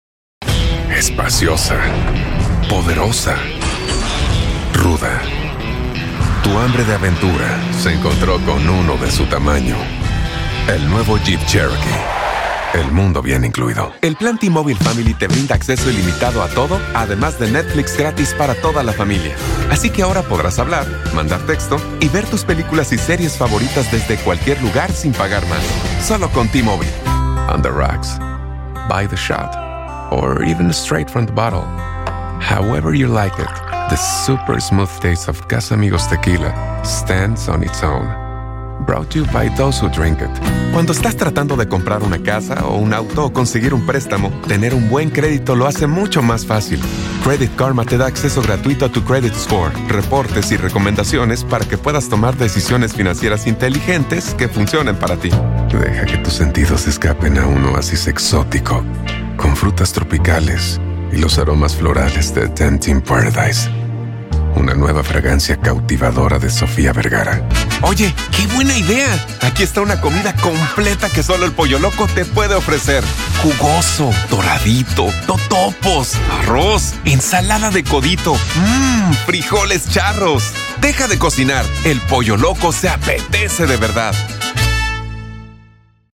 Commercial Reel (Spanish)